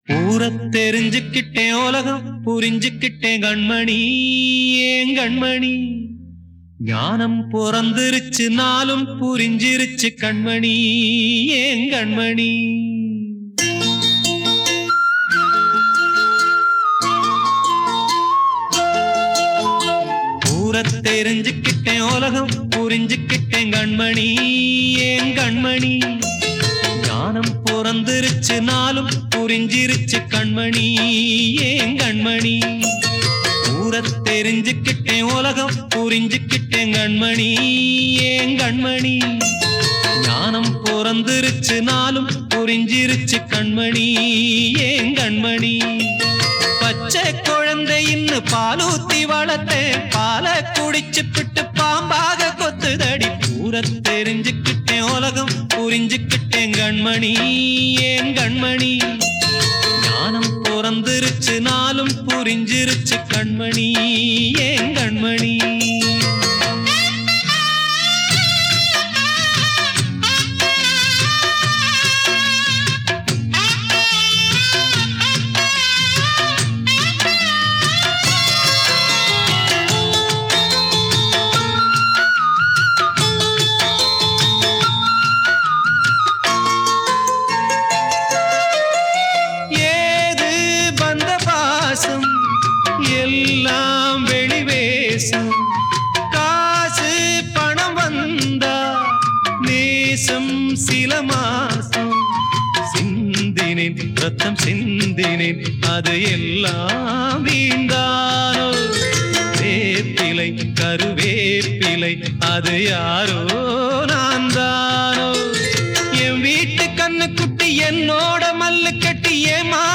Tamil VINYL LPs